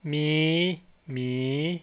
In the second picture "ມີຫມີ" a high-rising tone is followed by a low-rising tone.